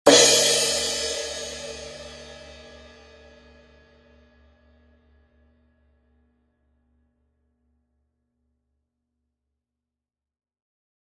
The Zildjian 16 A Custom Crash Cymbal has a natural, bright, not too high, not too low sound. Speaks very quickly.